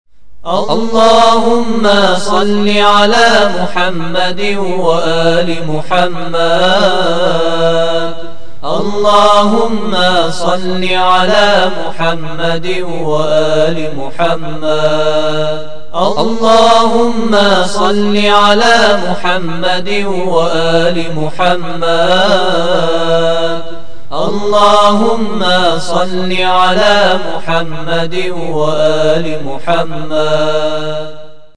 گالری صوتی صلوات
Sounds of Salavaat